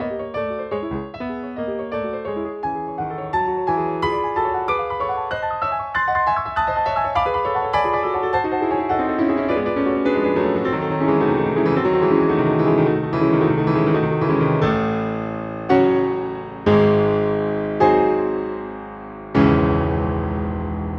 Pour illustrer le propos j'ai extrait du Prélude en do# de Rachmaninov la partie sollicitant le plus la polyphonie : d'abord avec une limite à 128, puis à 500 (la limite n'est alors pas atteinte)
(Restitutions faite par Ivory American Concert D).